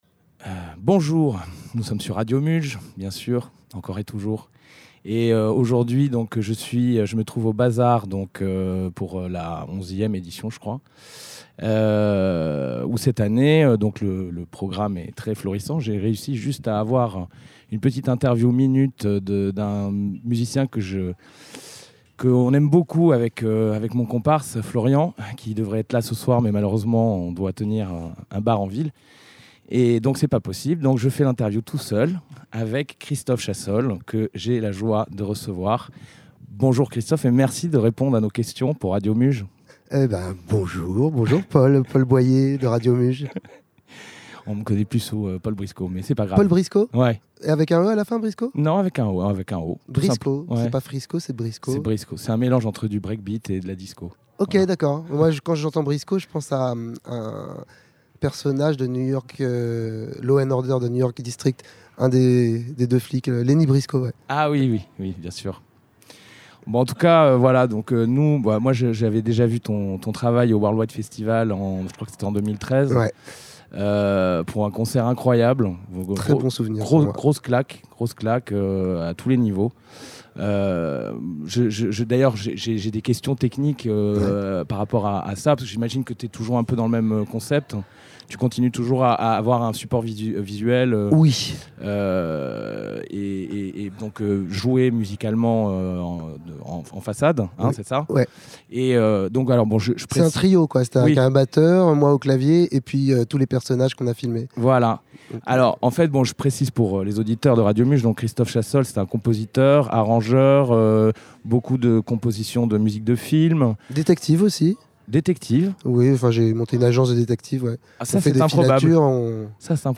ITW réalisée & enregistrée le 9/12/23 pendant le festival BAZ'R aux Chais des Moulins à Sète.